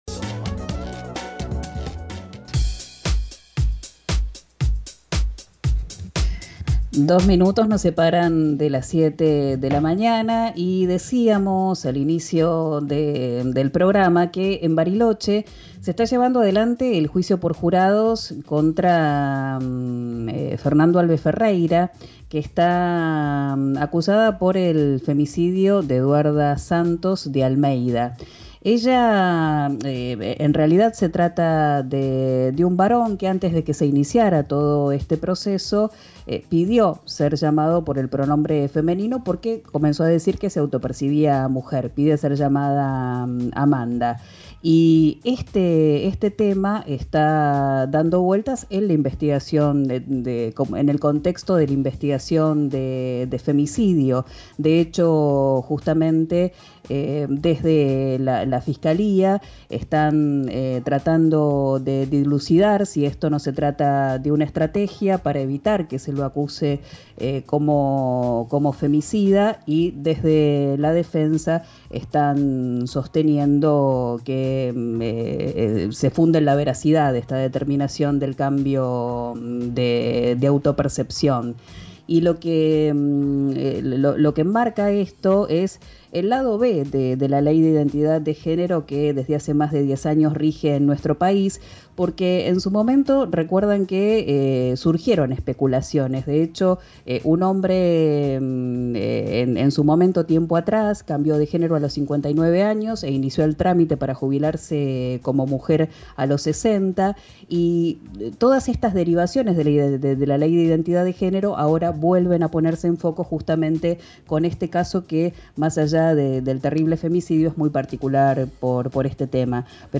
analizó el caso en RÍO NEGRO RADIO y despejó algunos interrogantes.